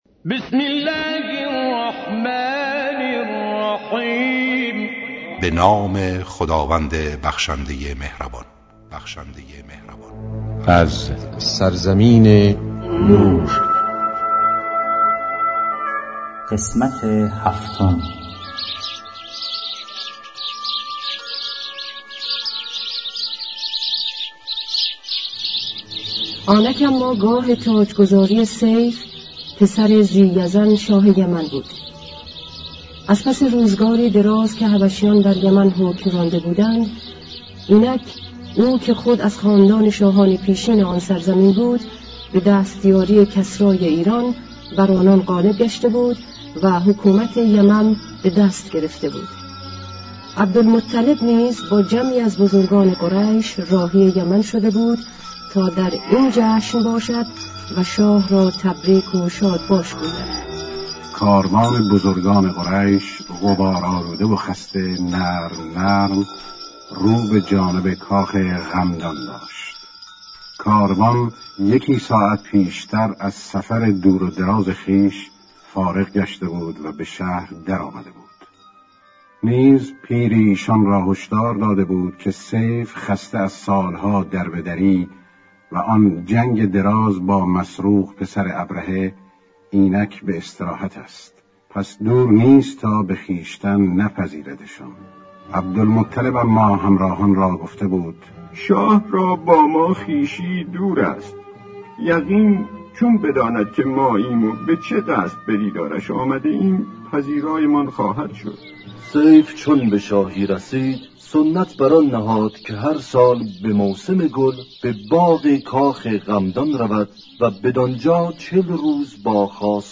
تمام قسمت‌های داستان شنیدنی زندگی پیامبر اسلام (ص) و امام علی (ع)؛ با اجرای مشهورترین صداپیشگان، با اصلاح و صداگذاری جدید
کتاب گویا